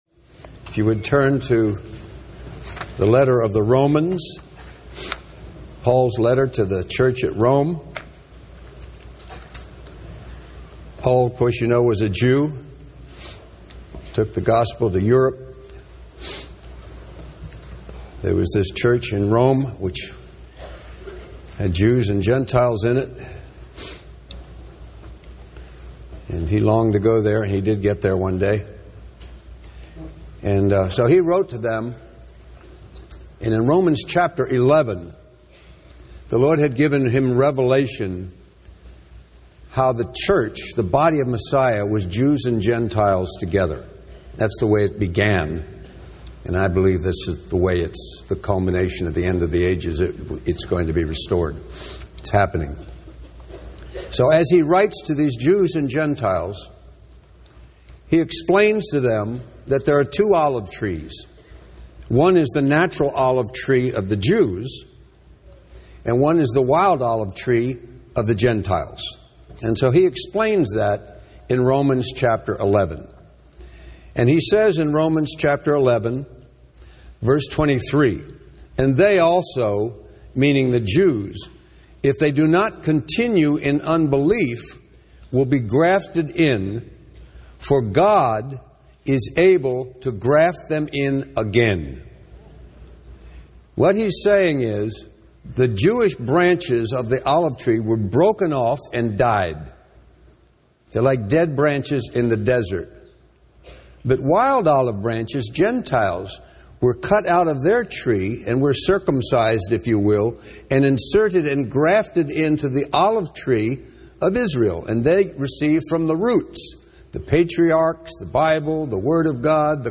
In this sermon, the speaker shares about their experiences in Israel during a time of drought and political uncertainty. They emphasize the power of God to provide and sustain His people even in difficult times. The speaker also highlights the importance of generosity and caring for others, as God's economy multiplies when we give.